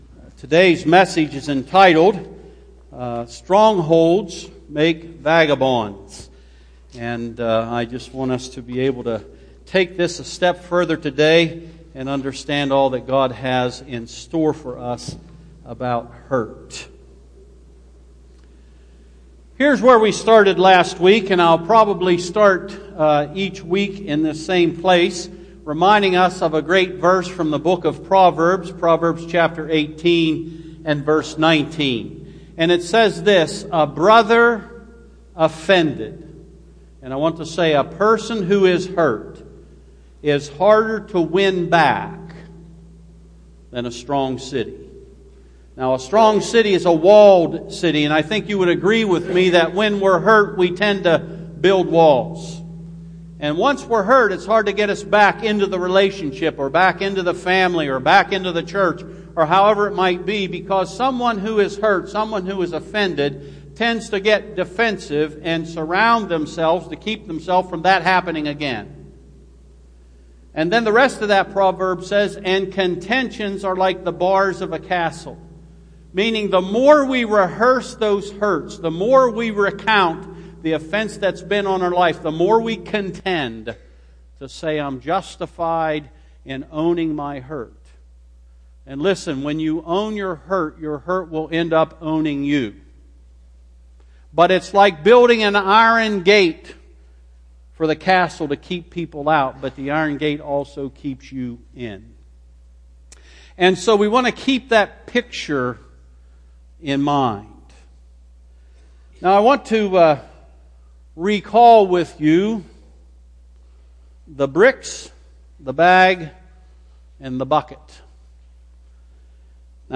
Sermons – North Street Christian Church